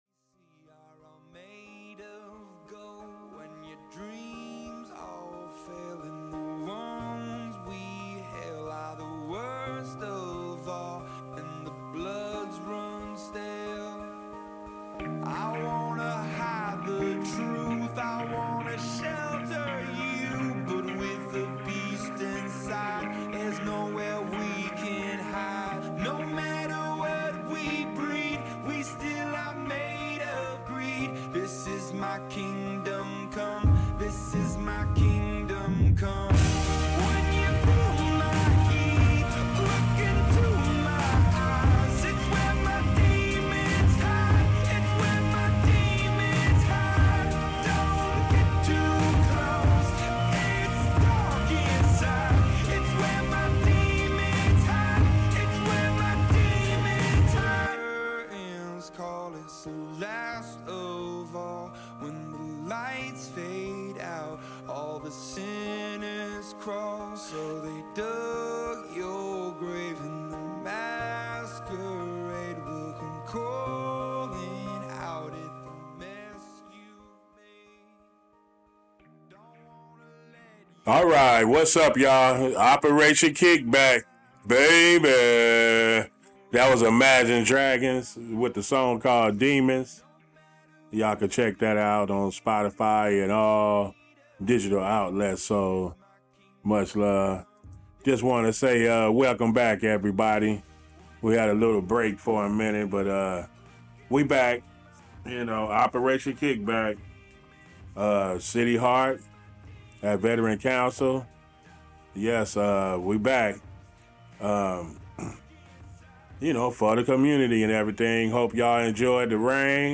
This episode of Operation Kickback aired live on CityHeART Radio on Tues. March 12, 2024 at 1pm.